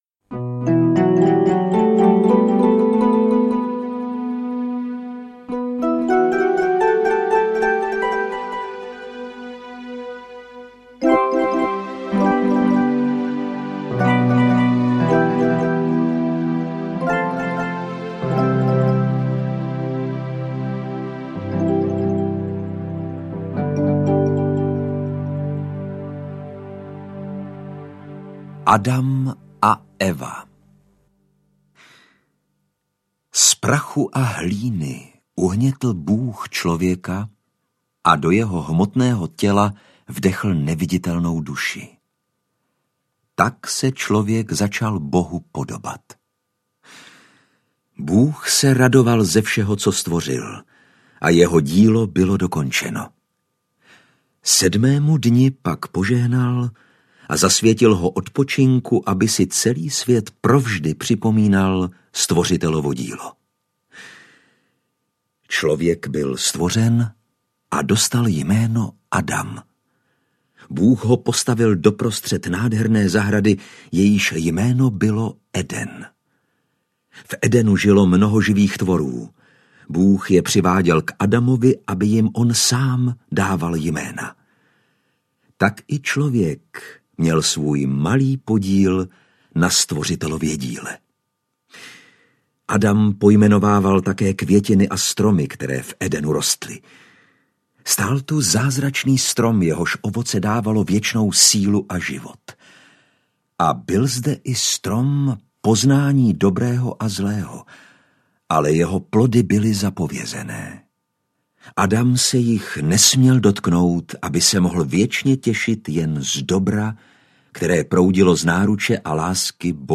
Obrazy ze Starého zákona audiokniha
Ukázka z knihy
Autorská kniha Renáty Fučíkové přibližuje současným jazykem starodávné biblické příběhy, které se staly součástí tří světových náboženství a základem kulturní historie lidstva. Poslech krátkých kapitol v interpretaci Lukáše Hlavici se může stát společným rodinným zážitkem a školním vodítkem k pochopení významných děl světového umění.
• InterpretLukáš Hlavica